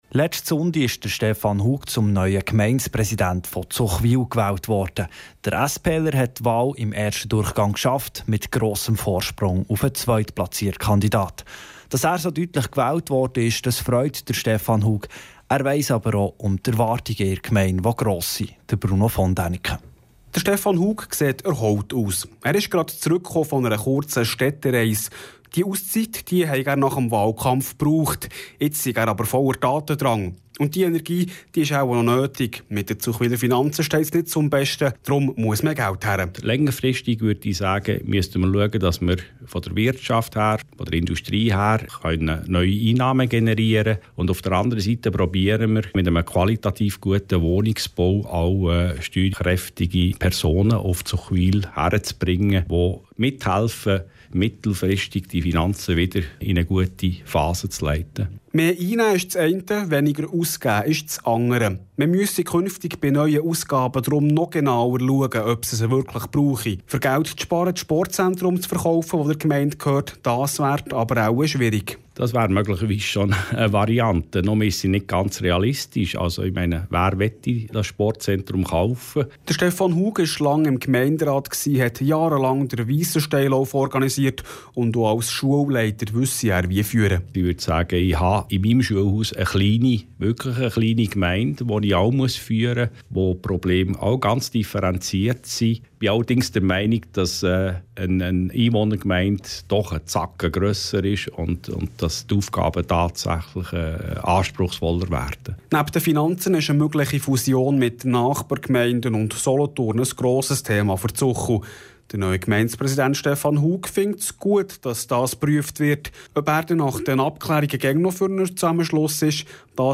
hören  Interview mit gewähltem Gemeindepräsidenten (Radio32 vom 19.04.2013)